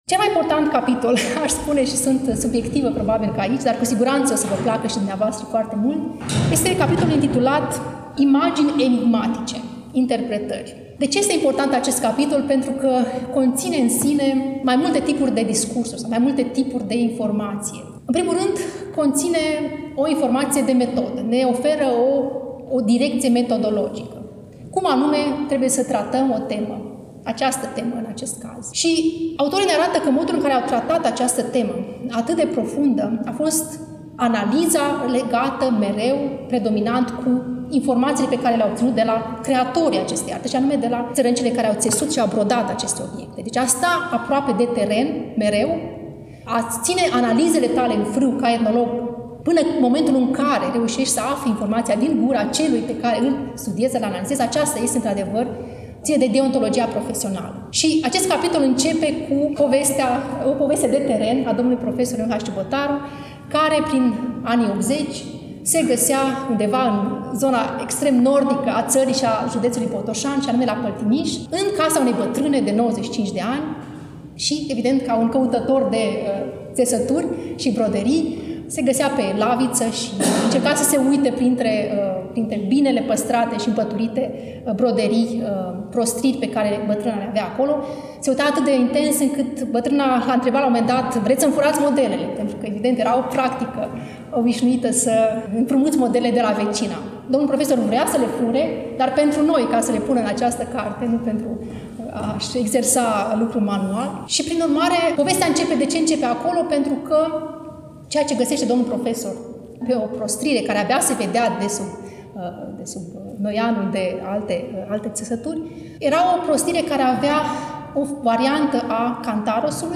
Volumul a fost lansat, la Iași, nu demult, în Sala „Petru Caraman” din incinta Muzeului Etnografic al Moldovei, Palatul Culturii.